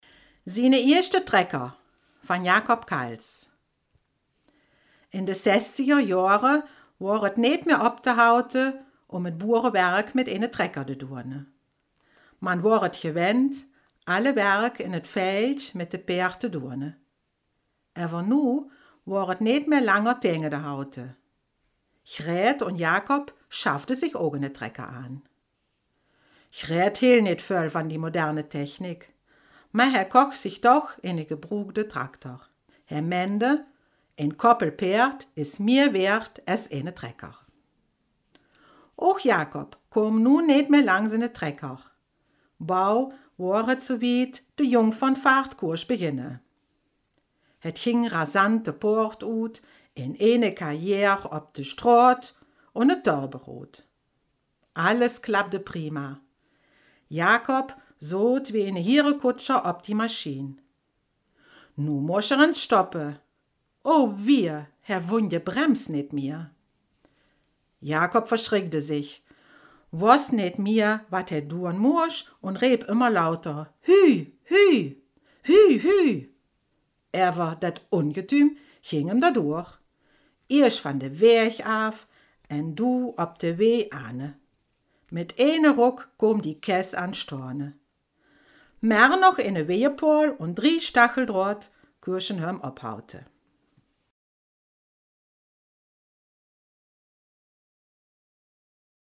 Selfkant-Platt
Geschichte
Ein Mundart Beitrags aus: Selfkant-Platt